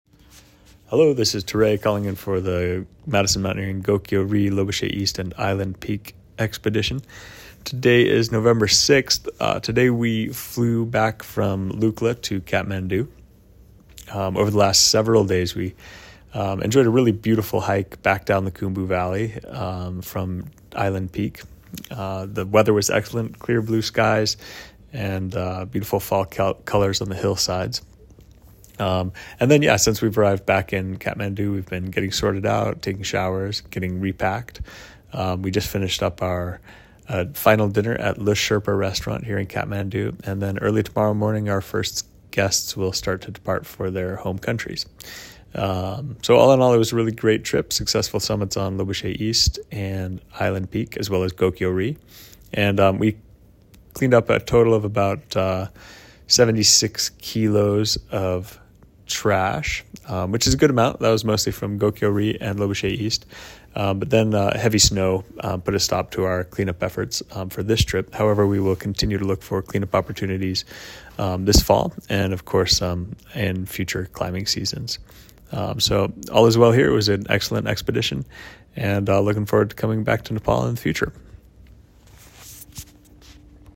• Enable the skill and add to your flash briefing to hear our daily audio expedition updates on select expeditions.